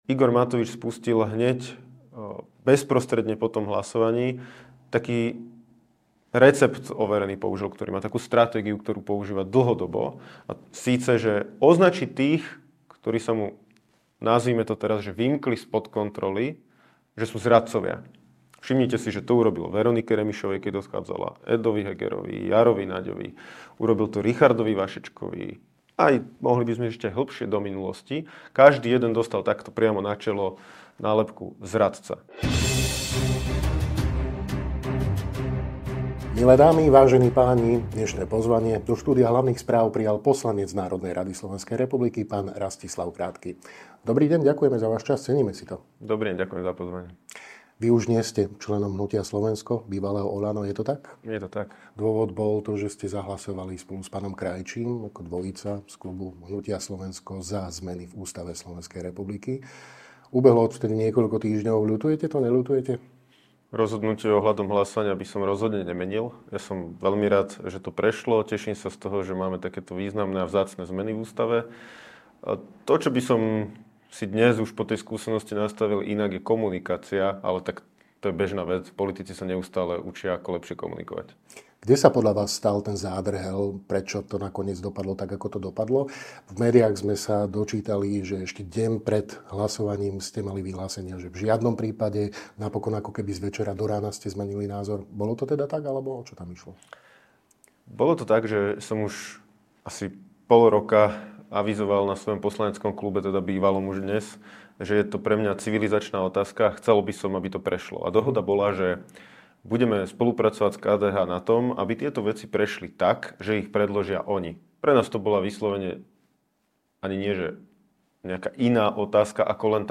Dozviete sa vo videorozhovore s bývalým poslancom Hnutia Slovensko, Mgr. Rastislavom Krátkym.